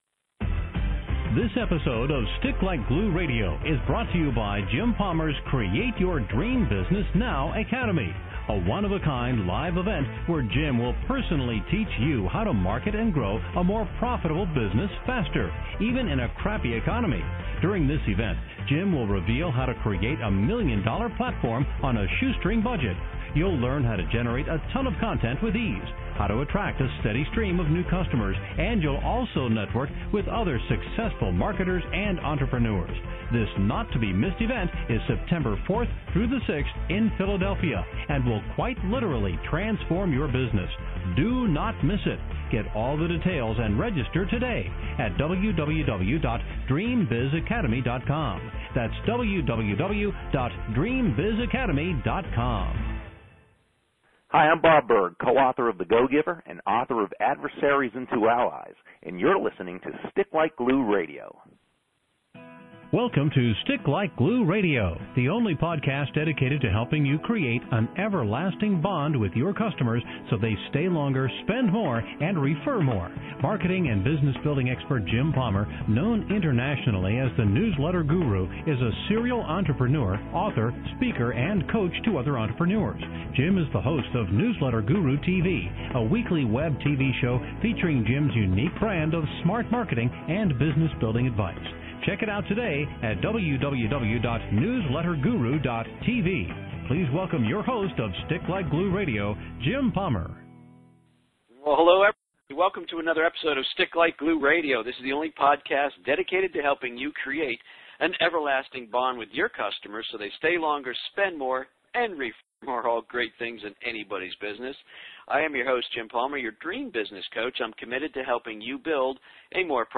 Stick Like Glue Radio #117 On this episode of Stick Like Glue Radio I interview bestselling author